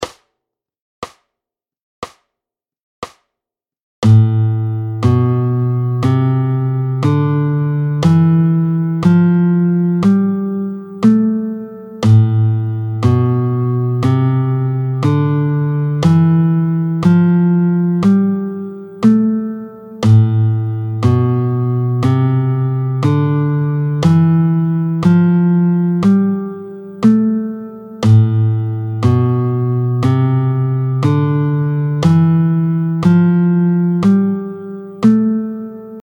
24-01 La mineur naturel, tempo 60